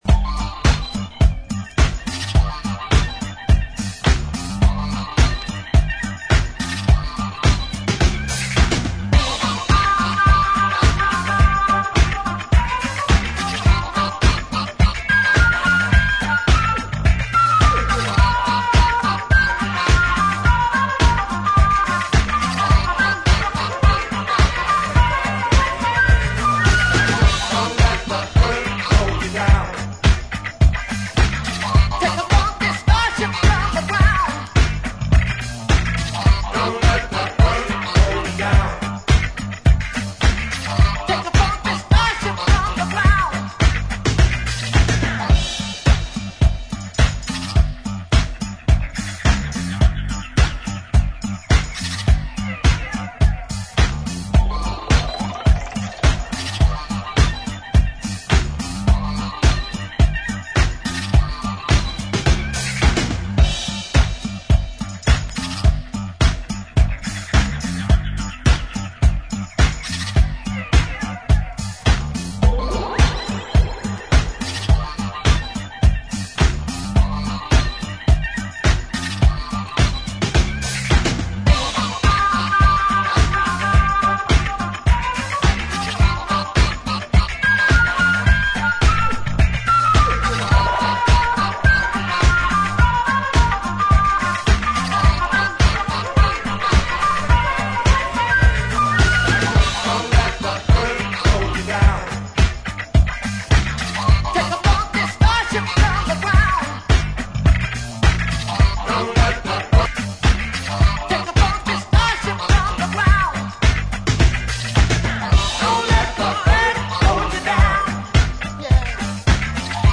ジャンル(スタイル) DISCO / SOUL / FUNK / NU DISCO / RE-EDIT